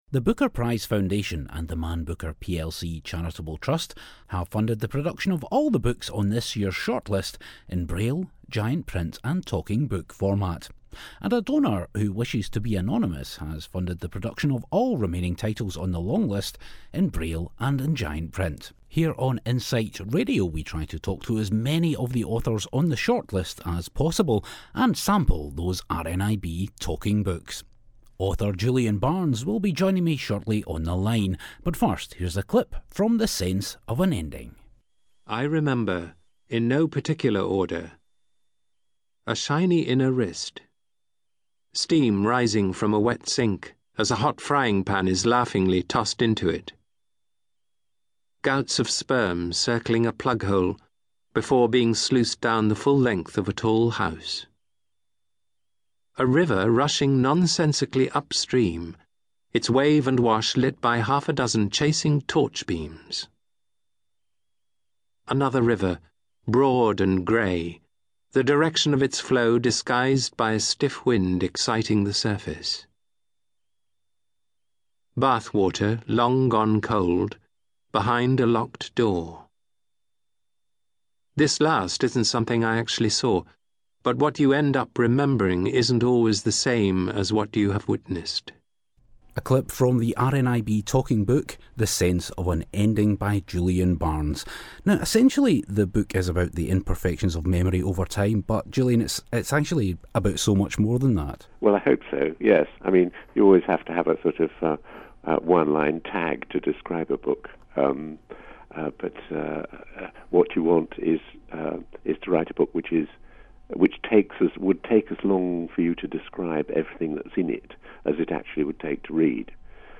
talks to Julian Barnes about 'The Sense of an Ending' before it won the Booker in 2011